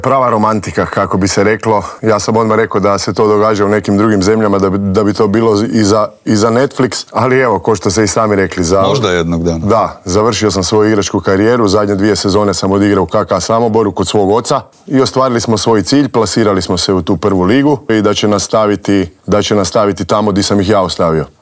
Iz godine u godinu se nadamo da će nam taj naš sport krenuti uzlaznom putanjom, a na čelo kao novi sportski direktor Hrvatskog košarkaškog saveza došao je tek umirovljeni igrač, Krunoslav Simon, koji je bio gost Intervjua tjedna Media servisa.